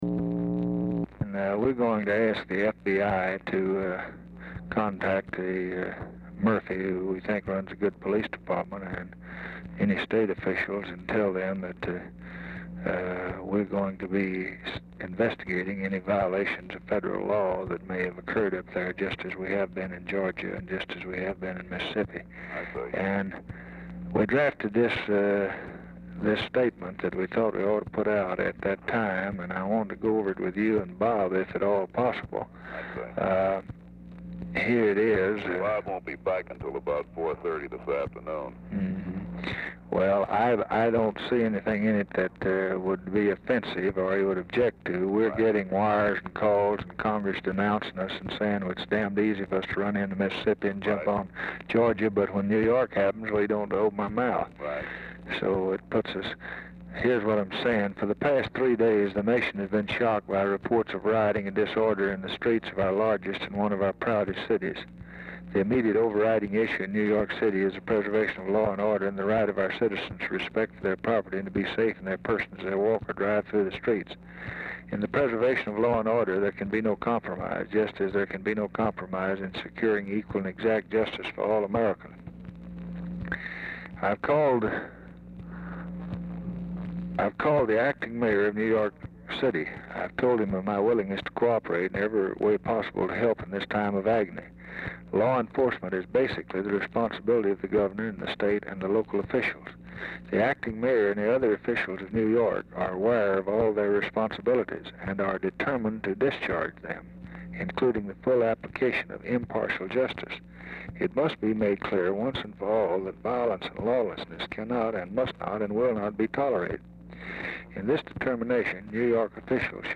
Telephone conversation # 4289, sound recording, LBJ and PAUL SCREVANE, 7/21/1964, 12:35PM
Format Dictation belt
Location Of Speaker 1 Oval Office or unknown location